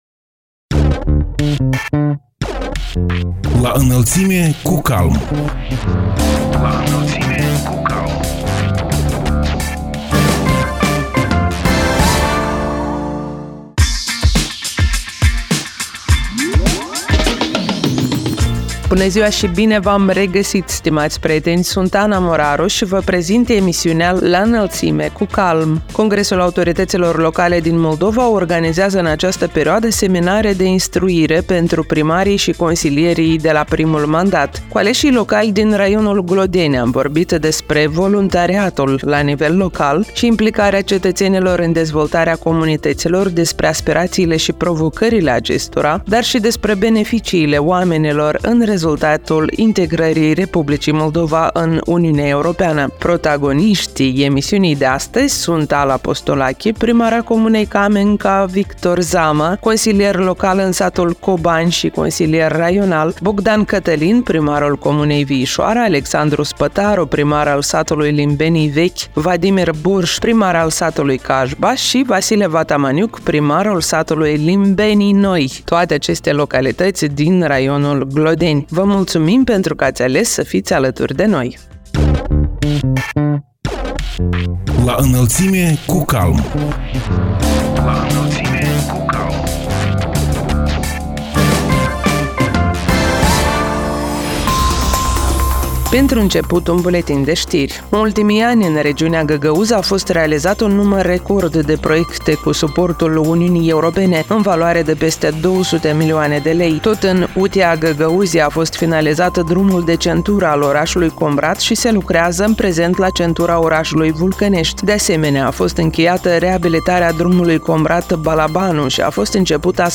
Cu aleșii locali din raionul Glodeni am vorbit despre voluntariatul la nivel local și implicarea cetățenilor în dezvoltarea comunităților, despre aspirațiile și provocările acestora, dar și despre beneficiile oamenilor în rezultatul integrării Republicii Moldova în Uniunea Europeană. Protagoniștii emisiunii „La Înălțime cu CALM” sunt Ala Postolachi, primara comunei Camenca; Victor Zamă, consilier local în satul Cobani și consilier raional; Bogdan Cătălin, primarul comunei Viișoara; Alexandru Spătaru, primar al satului Limbenii Vechi; Vadim Borș, primar al satului Cajba și Vasile Vatamaniuc, primarul satului Limbenii Noi.